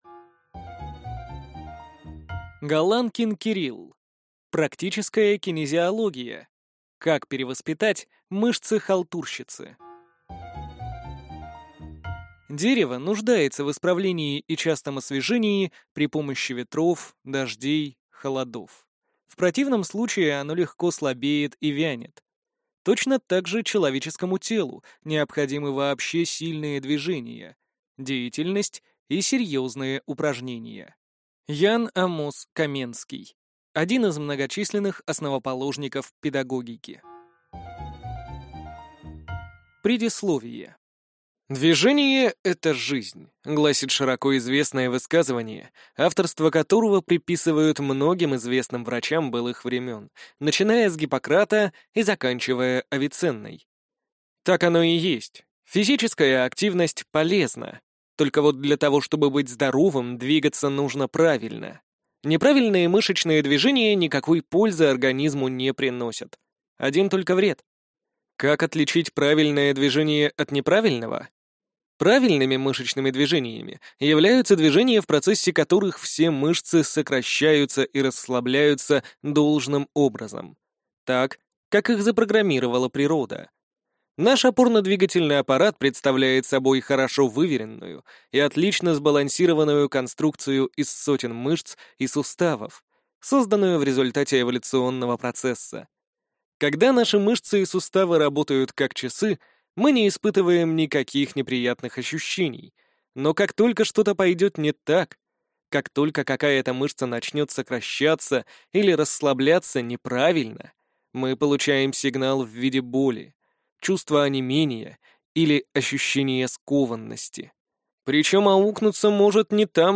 Аудиокнига Практическая кинезиология. Как перевоспитать мышцы-халтурщицы | Библиотека аудиокниг